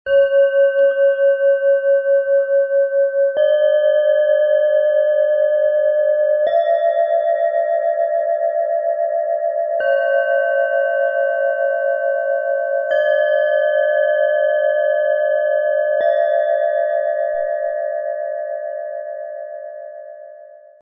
Zentrierung, Kraft und Herz - 3 Klangschalen Set mit klarem, stabilem Klang in schwarz-gold, Ø 10,4 - 11,4 cm, 1,06 kg
Die drei dickwandigen Klangschalen erzeugen einen klaren, durchdringenden und sehr stabilen Ton, der sammelt, aufrichtet und ausgleicht.
Der Klang dieses Sets wirkt nicht verspielt, sondern tragend, ruhig und präsent.
Die Schalen schwingen stabil, ohne aufzuwühlen, und eignen sich besonders für Menschen, die Klarheit, Kraft und innere Ausrichtung suchen.
Die unterste Schale wirkt freundlich, ruhig und sammelnd.
Der höchste Ton wirkt harmonisch, freundlich und herzlich.
Im Sound-Player - Jetzt reinhören können Sie den Original-Ton genau dieser drei Klangschalen des Sets in Ruhe anhören.
So erhalten Sie einen authentischen Eindruck vom klaren, stabilen und durchdringenden Klangcharakter dieses Schwarz-Gold-Sets.
MaterialBronze